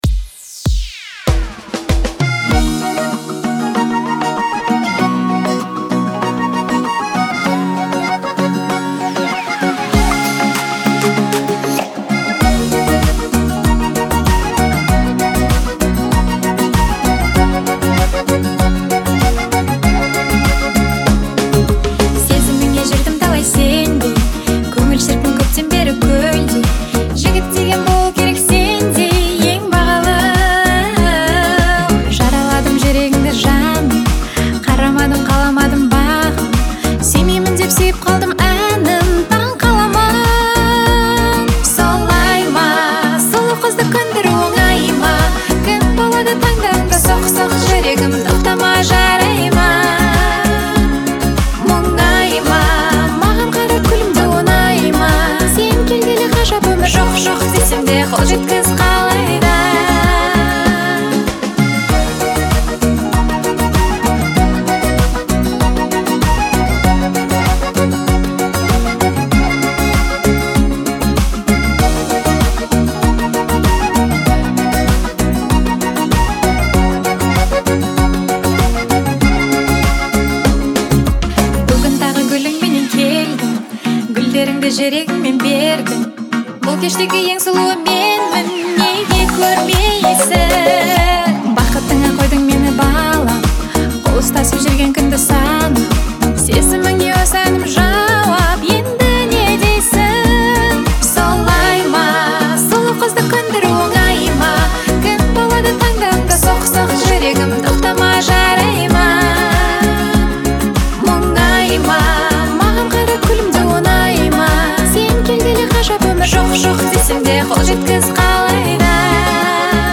это яркая и зажигательная композиция в жанре поп